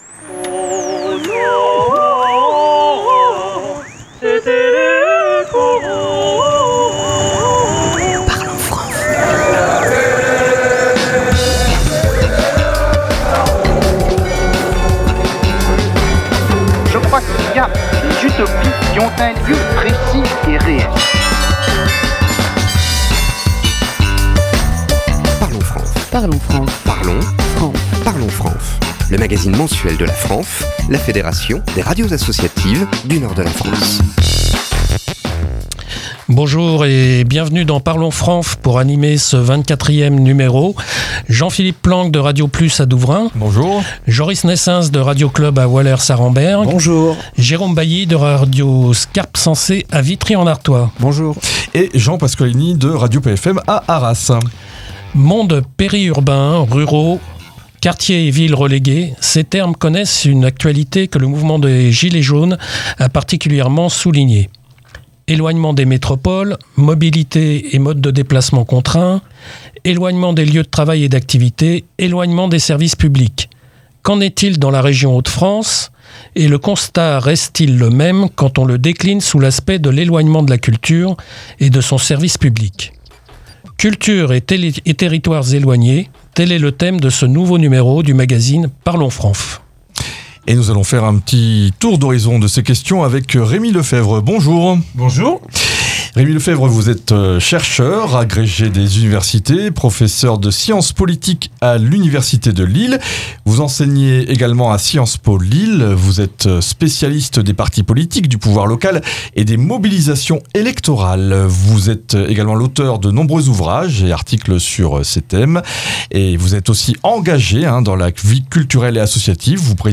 "Parlons FRANF", c’est le magazine des radios associatives du nord de la France.